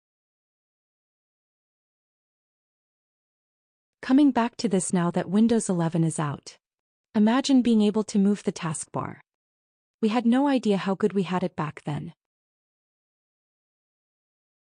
alternate VO plagiarized from the plagiarism machine